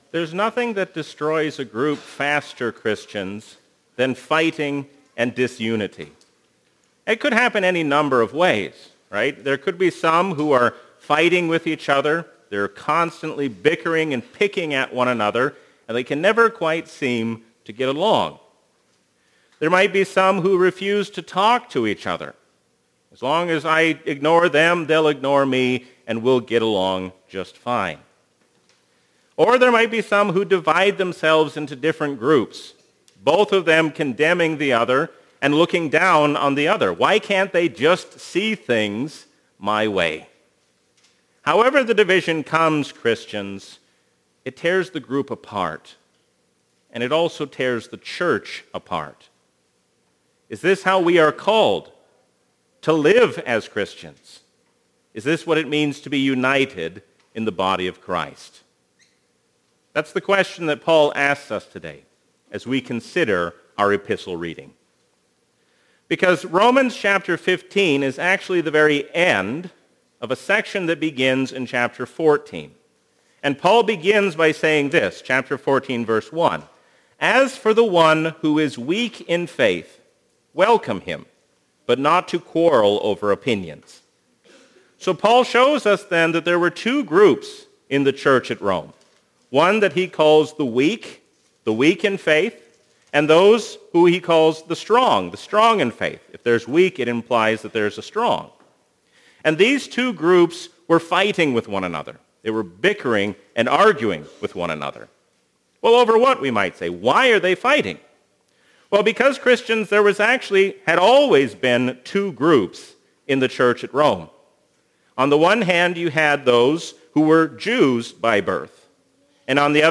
A sermon from the season "Trinity 2024." Let us seek to resolve our disputes in true unity and peace, because God has made us one in Jesus Christ.